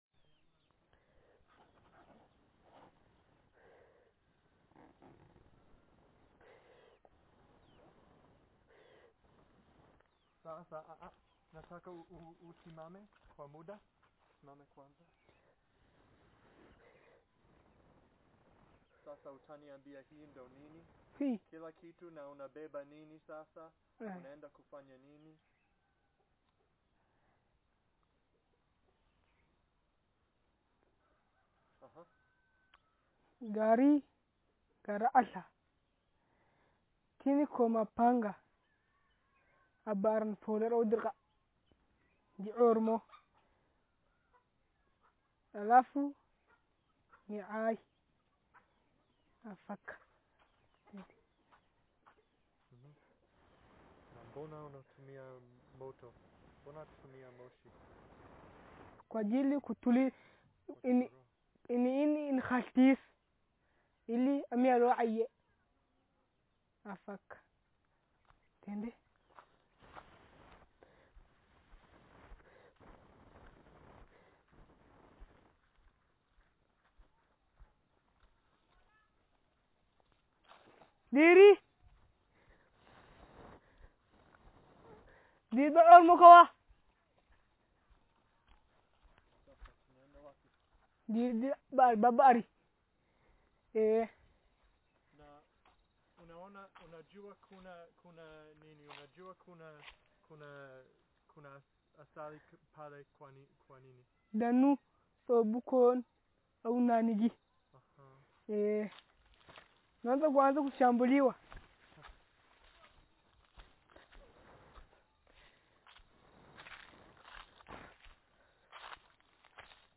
Speaker sex m Text genre personal narrative